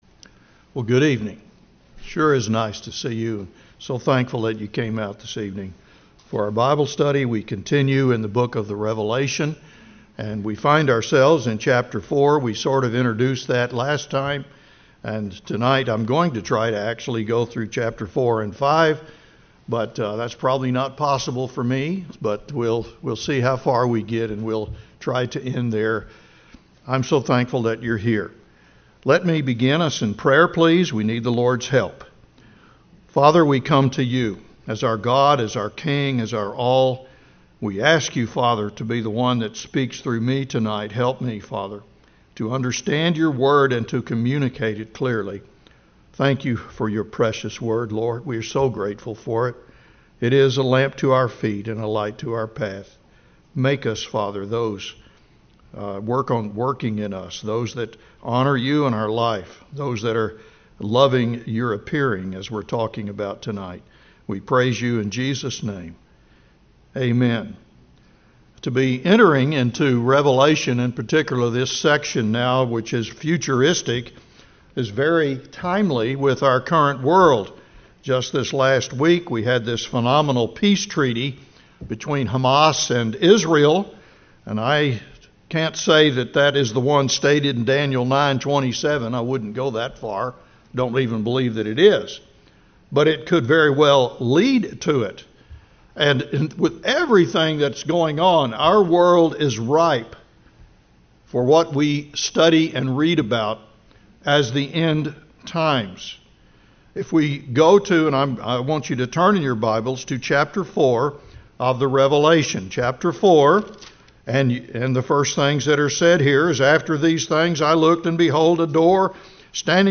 Grace Bible Chapel Non Denominational bible church verse-by-verse teaching